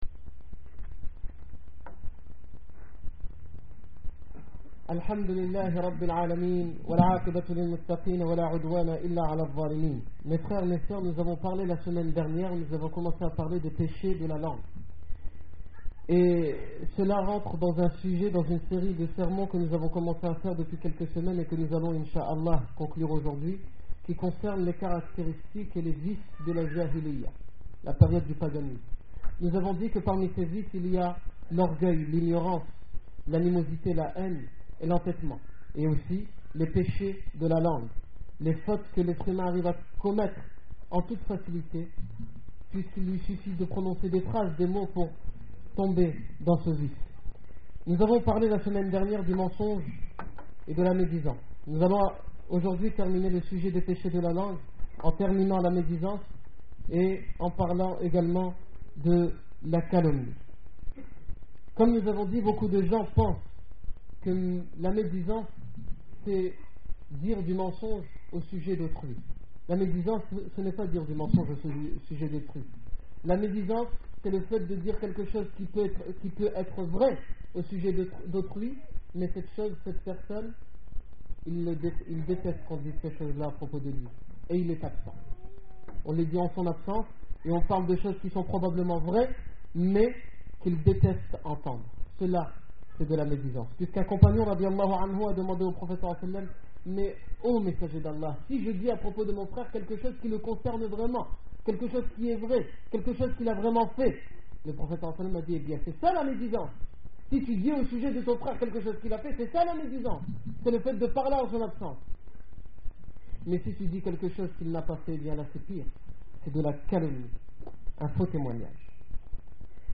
Discours du 7 mars 2008
Discours du vendredi